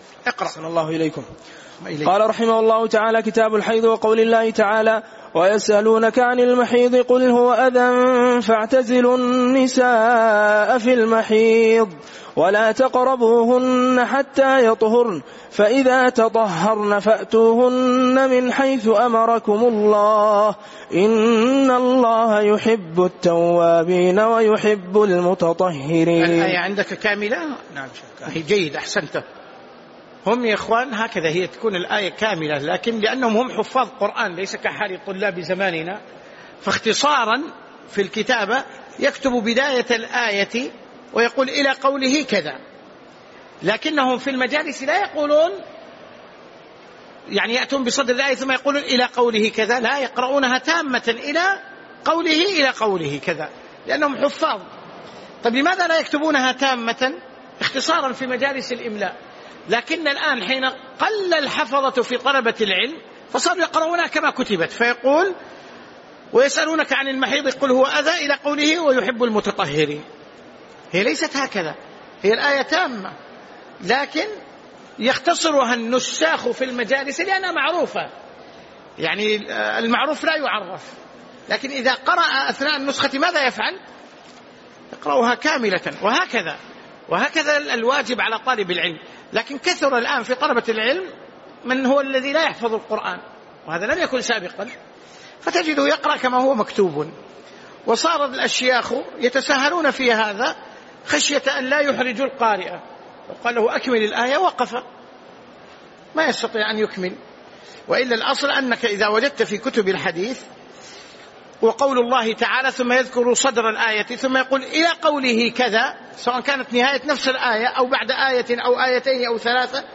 تاريخ النشر ٨ محرم ١٤٣٨ هـ المكان: المسجد النبوي الشيخ